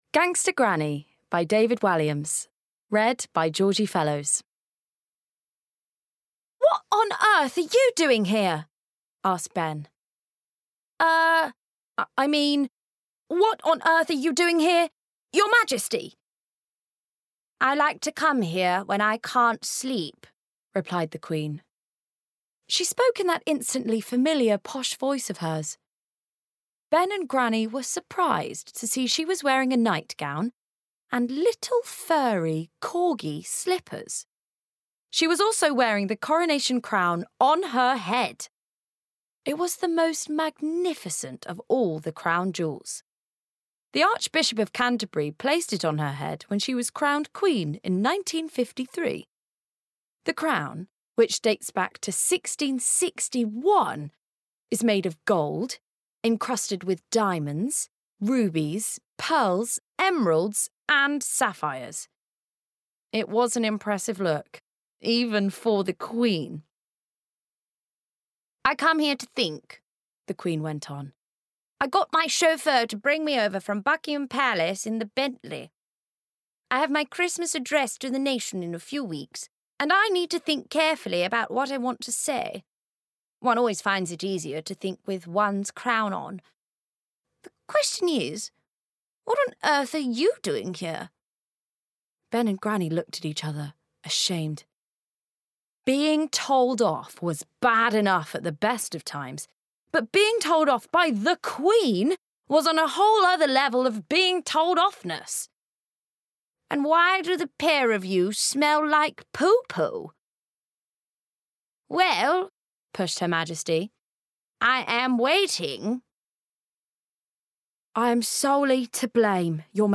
Modern/Fresh/Engaging
• Audio Books
Gangsta Granny by David Walliams (Various characters)